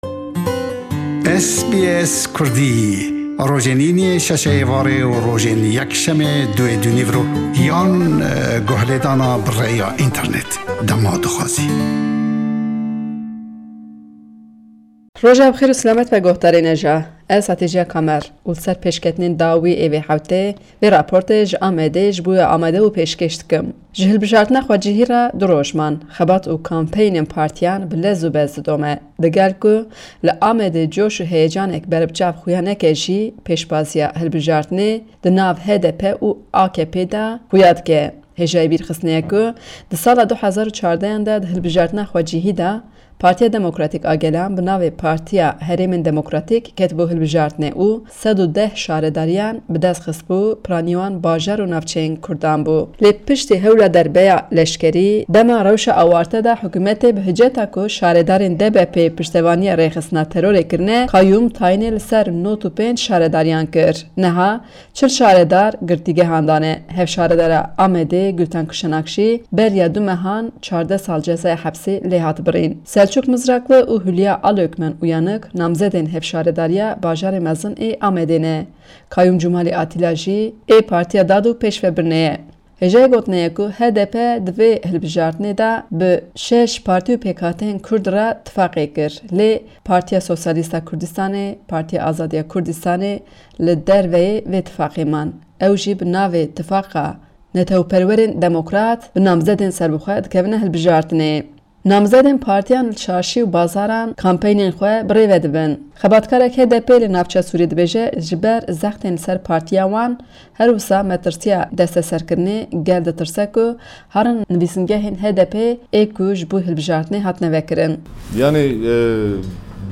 Raporta